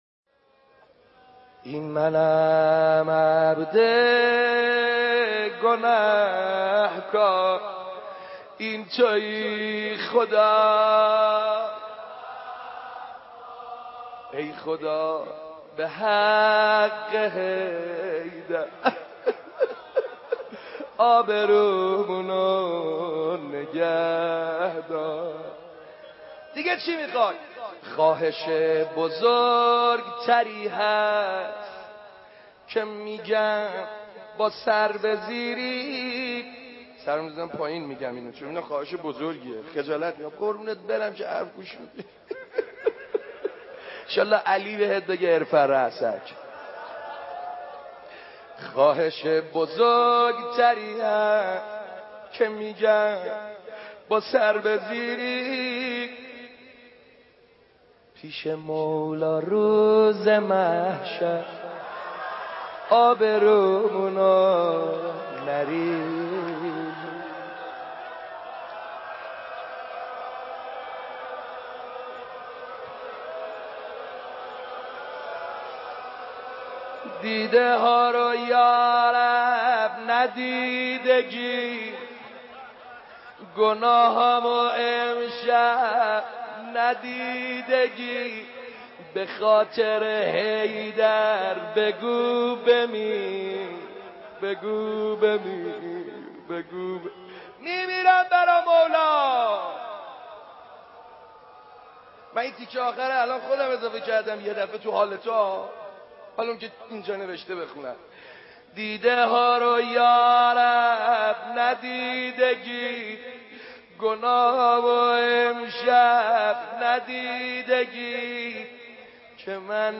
مناجات با خدا با صدای حاج سعید حدادیان -( این منم عبد گنهکار،این تویی خدای غفار )